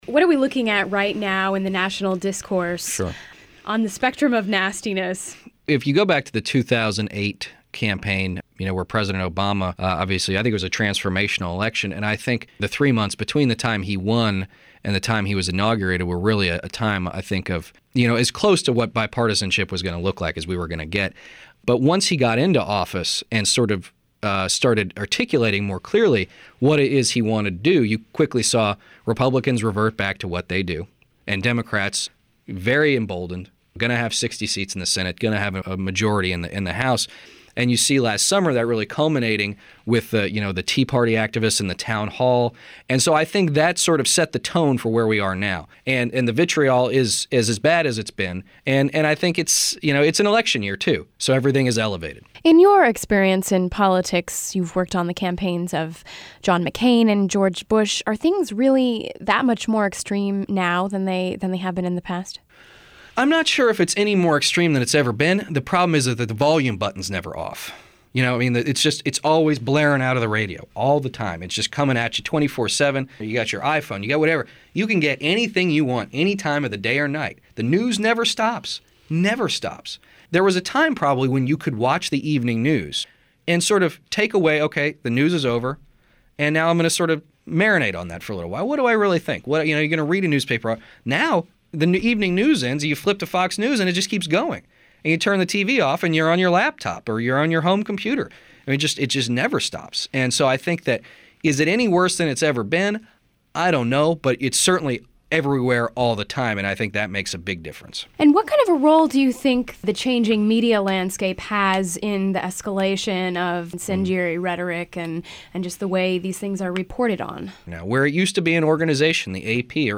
INTERVIEW: The "politics of nastiness."